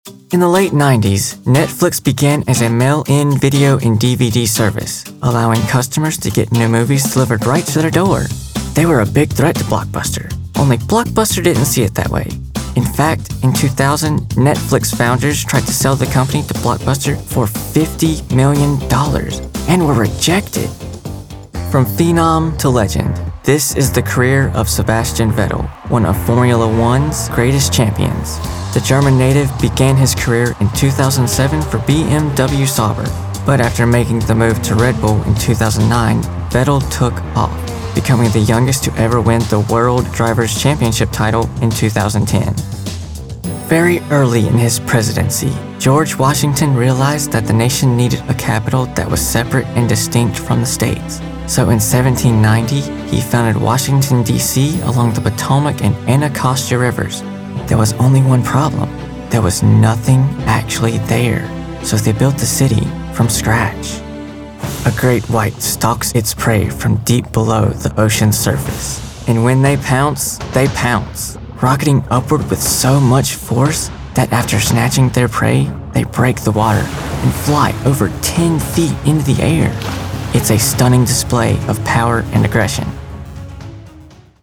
Male Voice Over Talent
Narrations
With a voice described as warm, respectful, and genuinely caring, I specialize in creating audio experiences that leave a lasting impression.